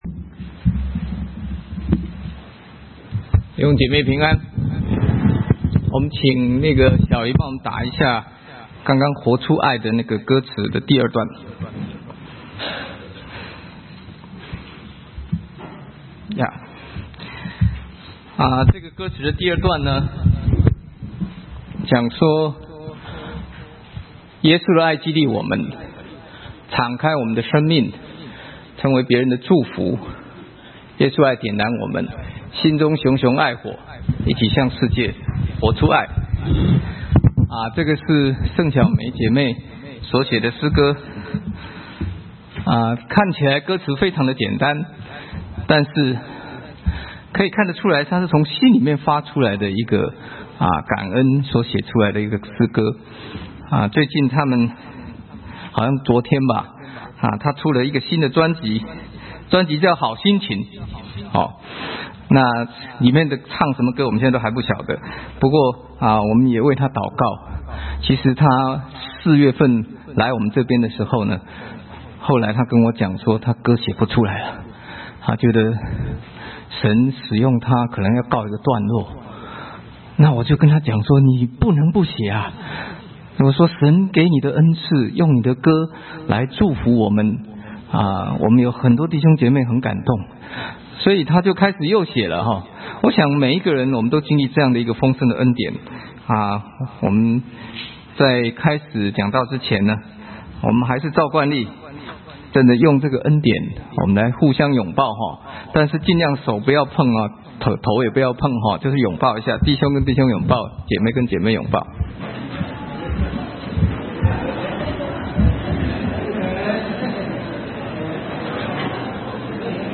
» 下載錄音 (很抱歉，这段录音有一些录制上的困难)